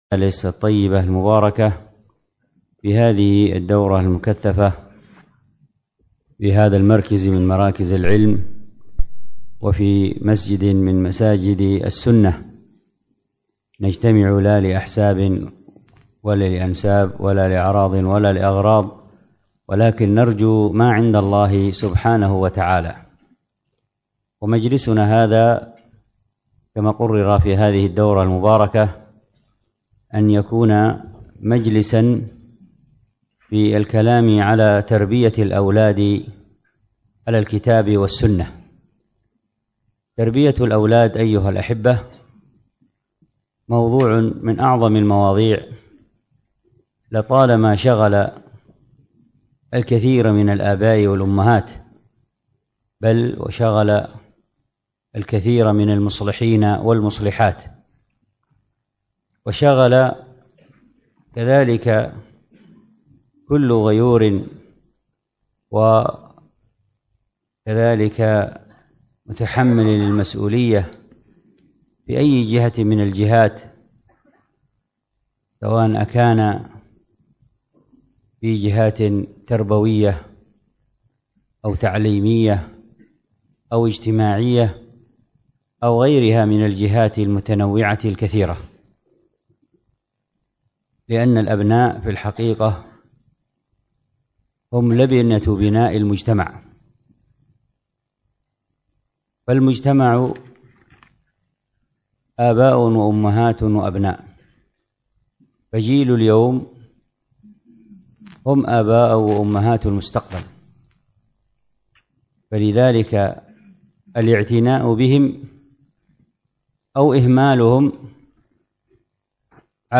الدرس في التعليقات على كتاب الأدب المفرد 303، ألقاها